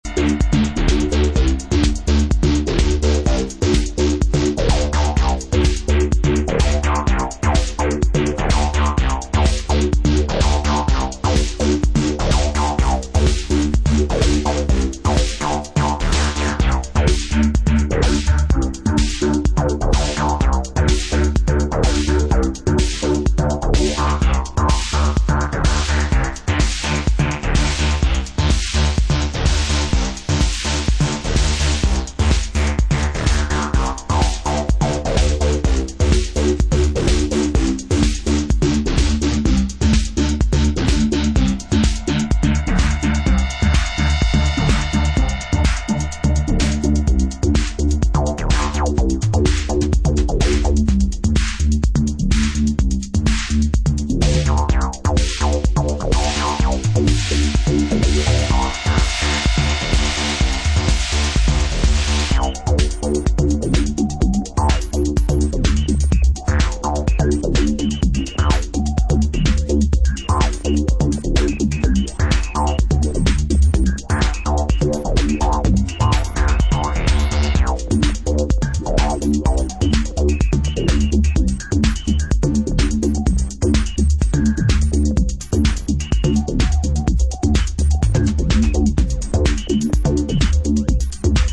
Не слишком минималистичное техно и электро